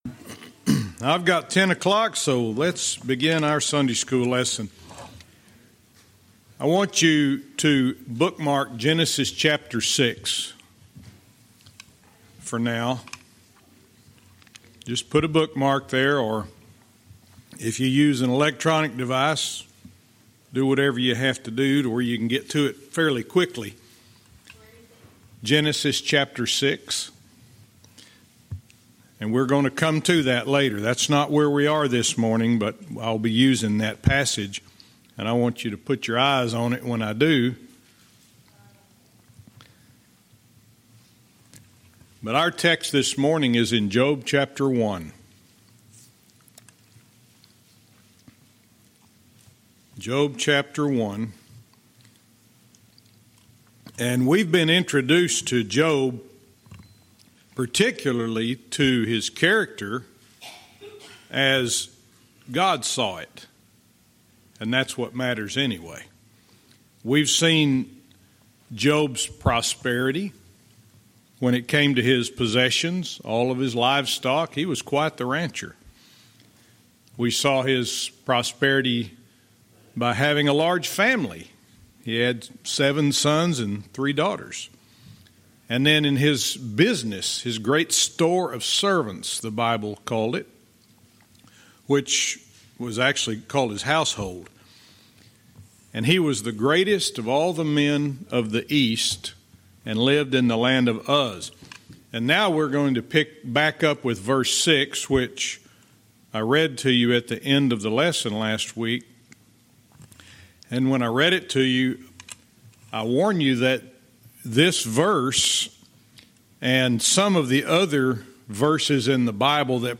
Verse by verse teaching - Job 1:6-8